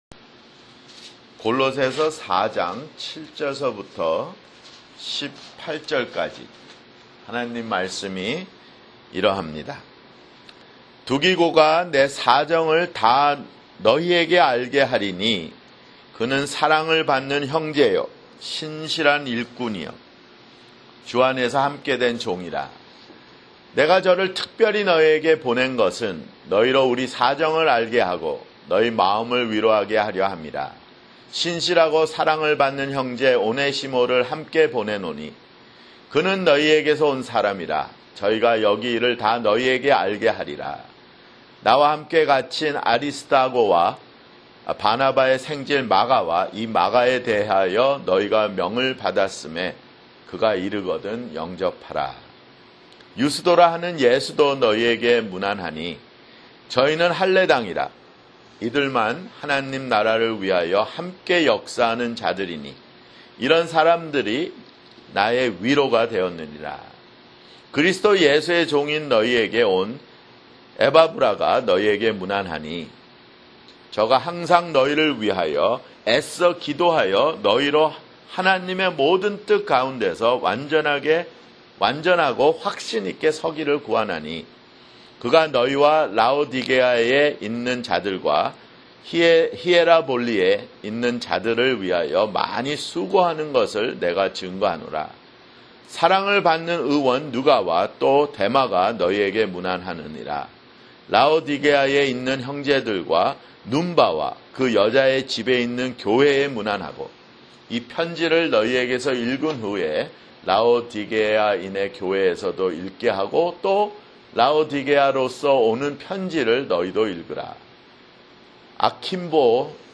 [주일설교] 골로새서 (86)